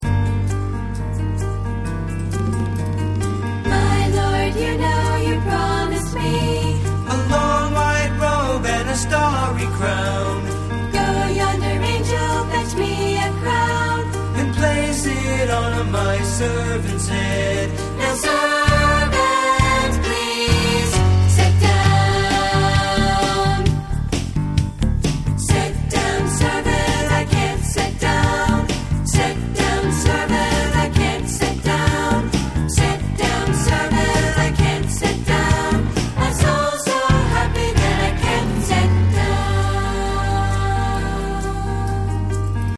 Composer: Spiritual
Voicing: Accompaniment CD